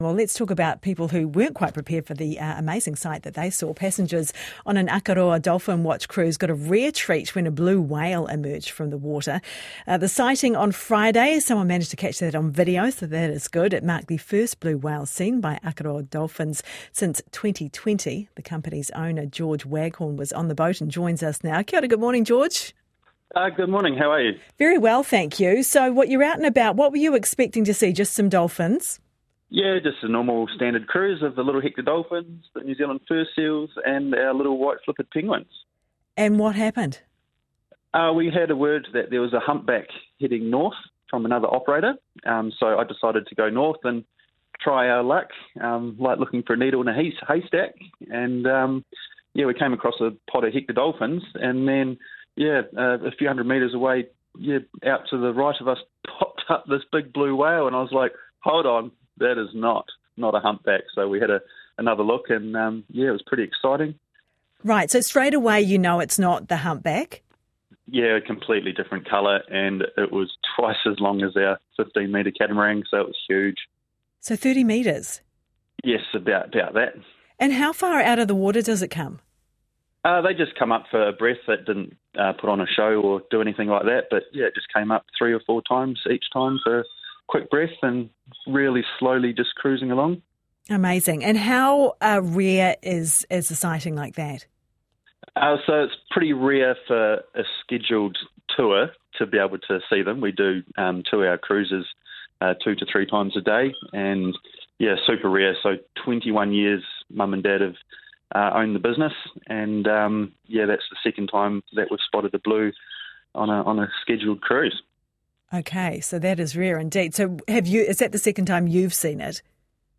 Rare blue whale sighting in Akaroa Harbour (Whale video + Audio interview)